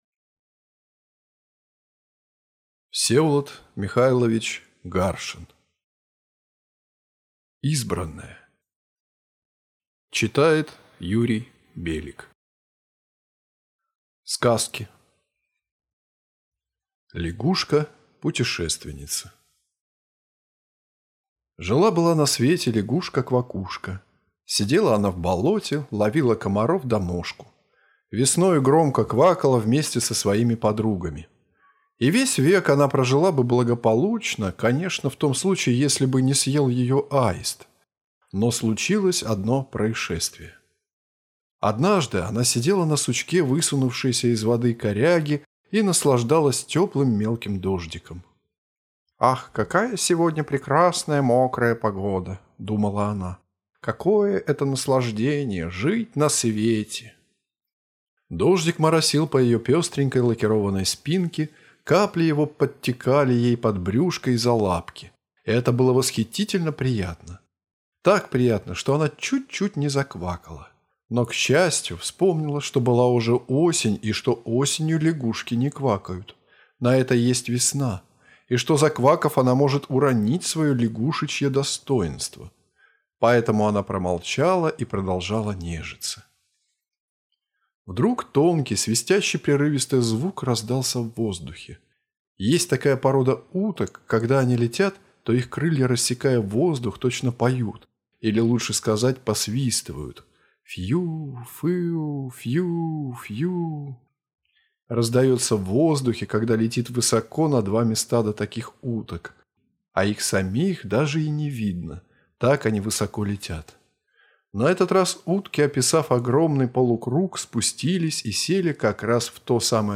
Аудиокнига Избранное | Библиотека аудиокниг